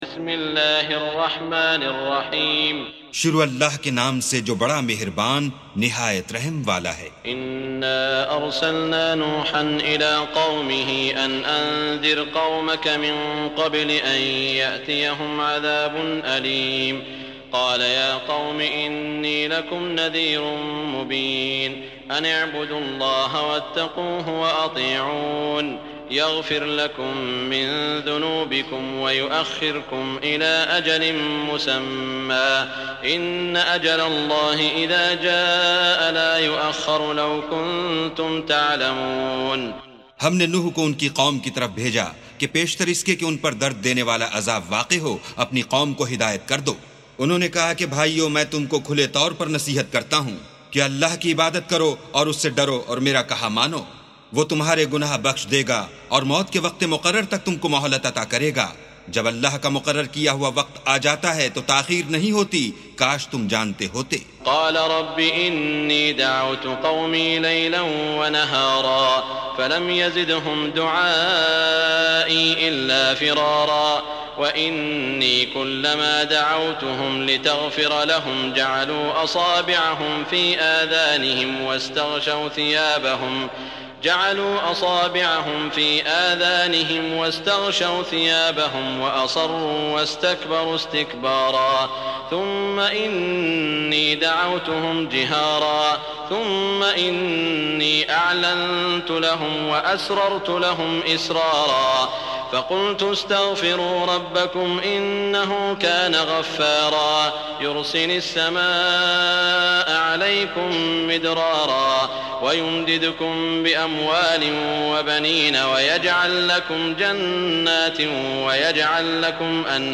سُورَةُ نُوحٍ بصوت الشيخ السديس والشريم مترجم إلى الاردو